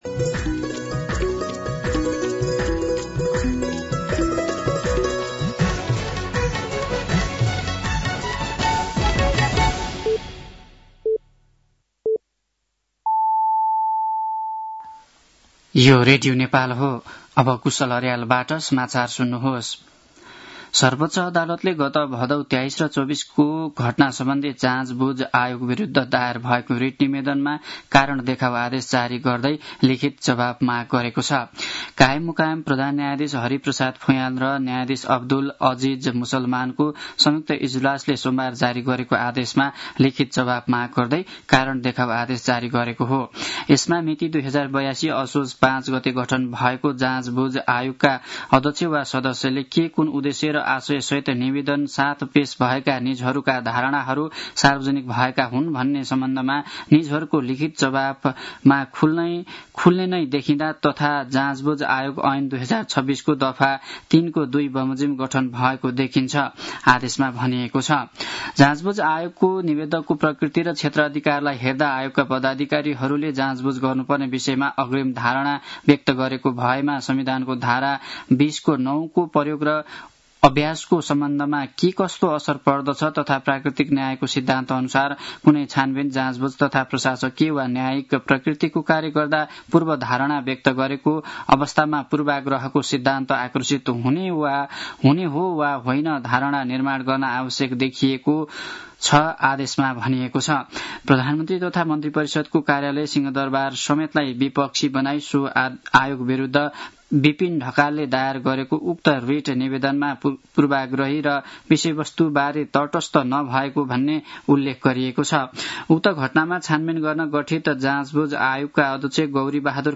साँझ ५ बजेको नेपाली समाचार : १९ कार्तिक , २०८२
5-pm-news-7-19-1.mp3